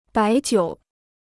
白酒 (bái jiǔ) Free Chinese Dictionary